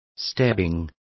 Complete with pronunciation of the translation of stabbing.